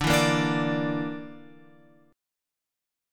D Suspended 2nd Sharp 5th